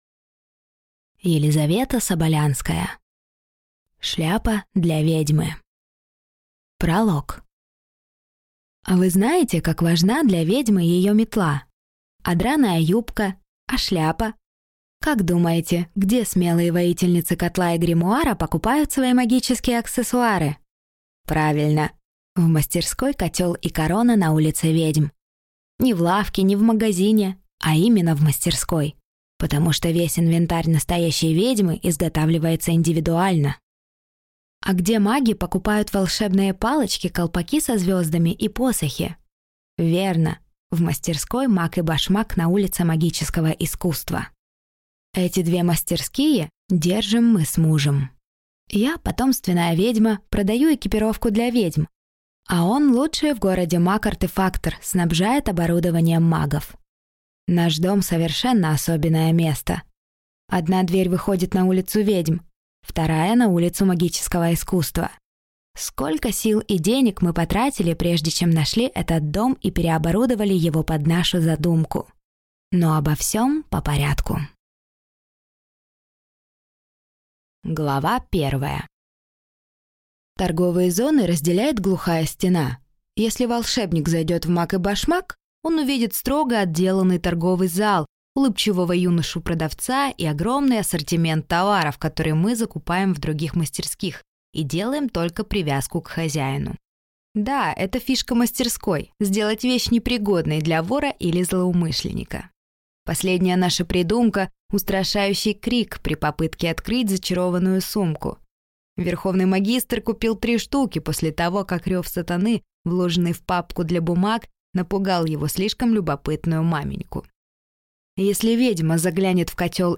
Аудиокнига Шляпа для ведьмы | Библиотека аудиокниг